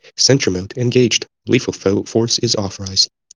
sentry-mode-engaged.wav